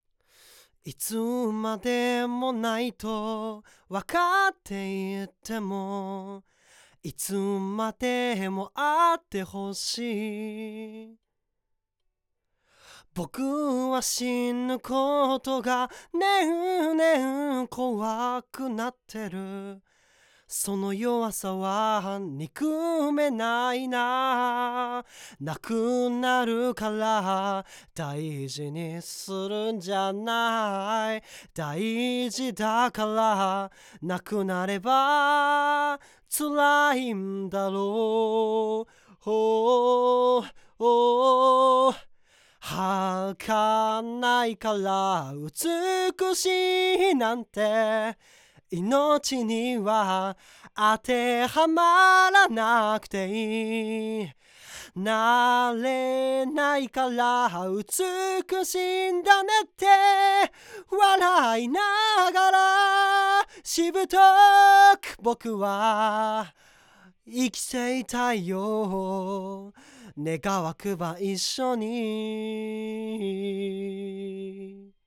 そこで今回は、サウンドスタジオノア都立大店の10階にあるRECスタジオで2種類のコンデンサーマイクを使用したボーカルレコーディングを行いました。
ポップガードを拳1つ分開けて設置し、ボーカルの鼻腔共鳴が強いということもあり、口元を少し斜めに狙うようにしました。
また、部屋鳴りの確認のためリフレクションフィルターは使用していません。
まず今回使用したRECスタジオの部屋鳴りについて、音は少しウェットよりになり、ナレーションやラジオ撮りに向いている印象でした。
NEUMANN U87Ai
2つの音の違いについて、明らかに音量がU87Aiの方が大きく、良い意味で特徴のないフラットなサウンドでした。